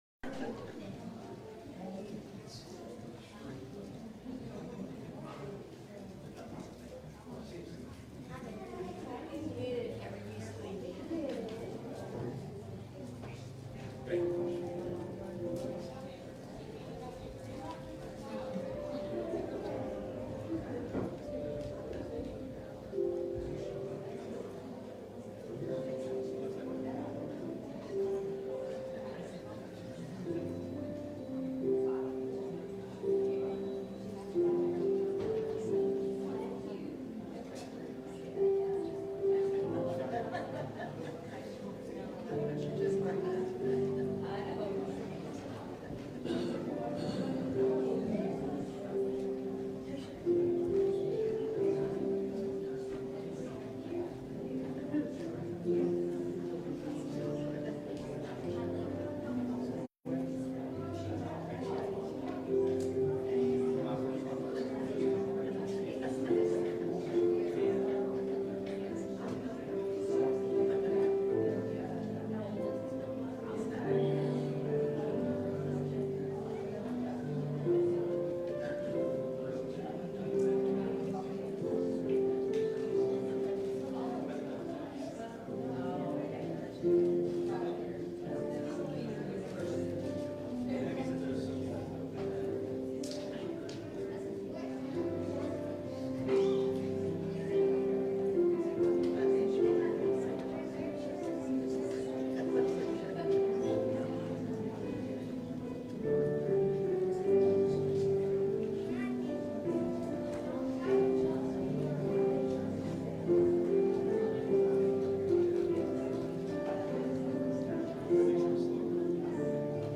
How to Live the Christian Life | SermonAudio Broadcaster is Live View the Live Stream Share this sermon Disabled by adblocker Copy URL Copied!